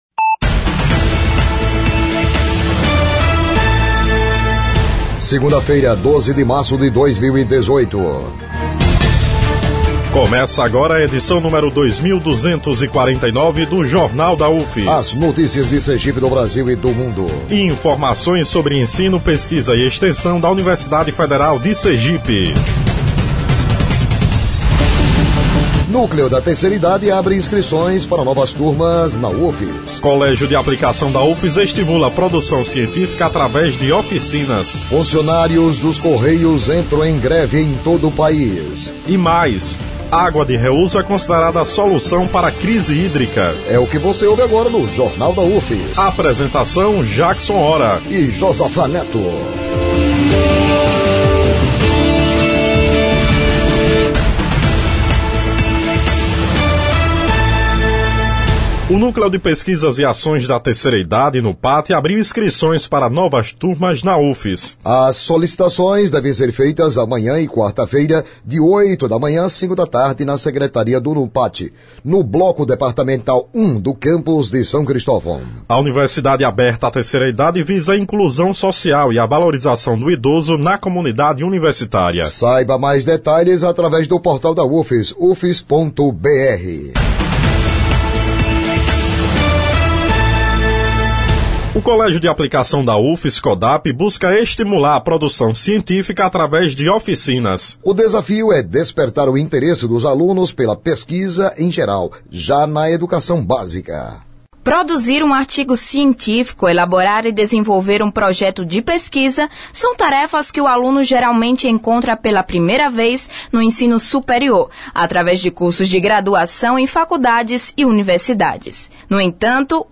O Jornal da UFS desta segunda-feira, 12, destaca que o Colégio de Aplicação da UFS busca incentivar a produção científica dos alunos da educação básica através de oficinas. O noticiário vai ao ar às 11h00 na Rádio UFS, com reprises às 17h00 e 22h00.